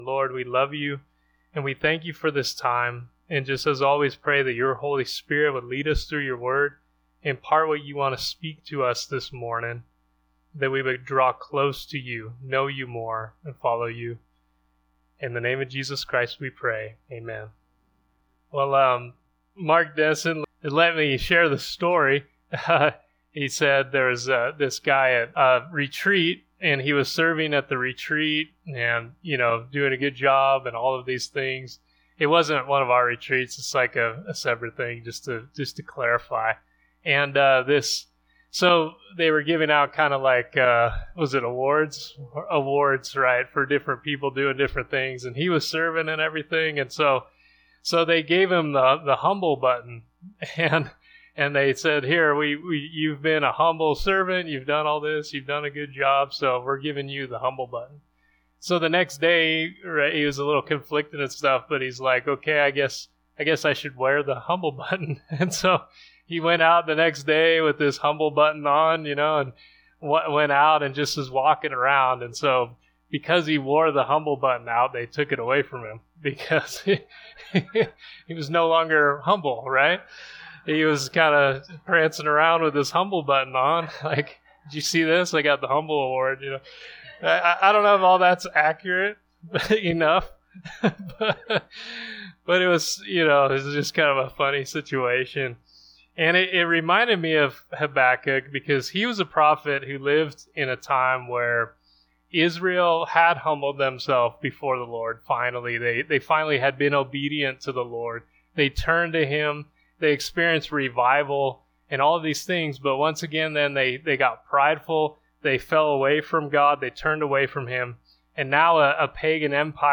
Audio Sermons - Warehouse Christian Ministries Reno/Sparks NV